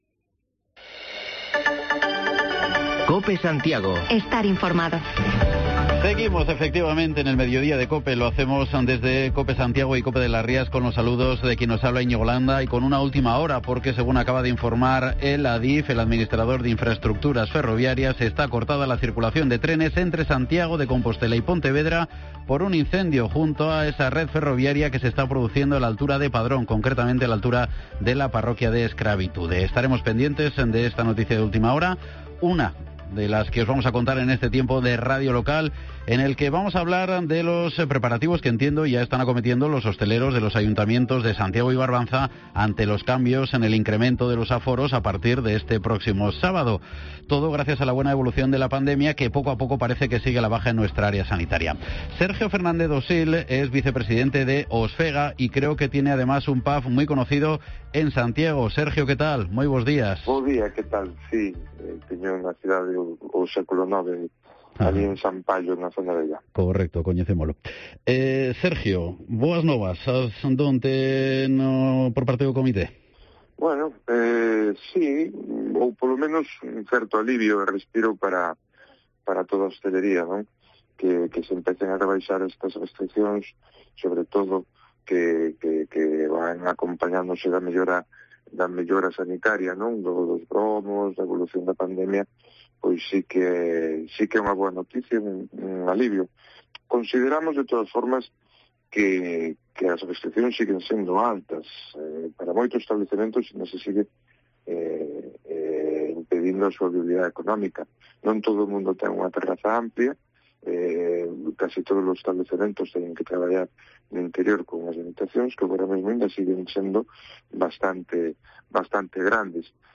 Podcast: Informativo local Mediodía en Cope Santiago y de las Rías 19/08/2021